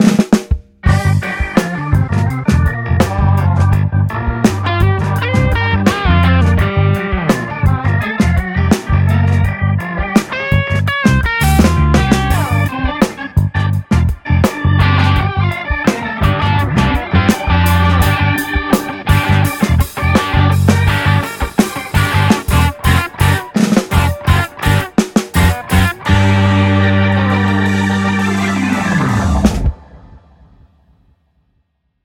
肝心の音はさすがに満足。
演奏や曲がヘボくても音質が良いだけでそれっぽく聞こえるから困ったものです（何が困るかというとそれを理由に金をかけてしまう）。